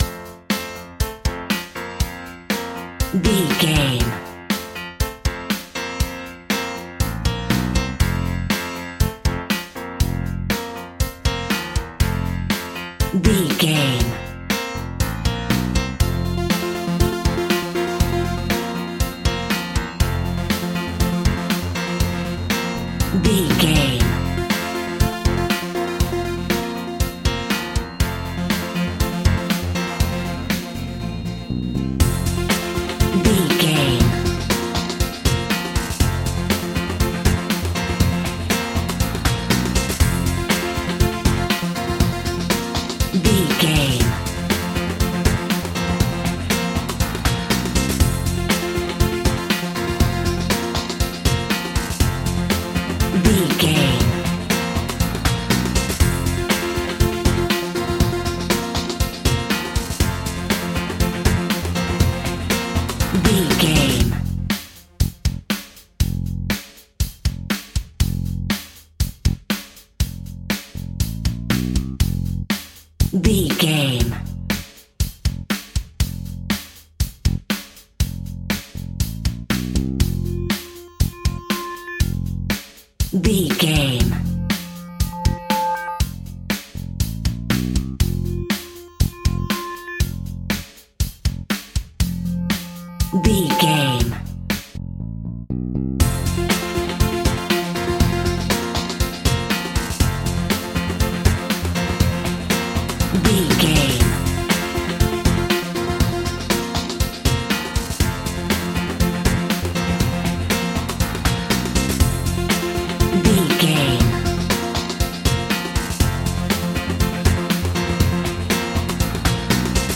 Ionian/Major
pop rock
indie pop
fun
energetic
uplifting
synths
drums
bass
guitar
piano